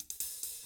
129BOSSAI1-L.wav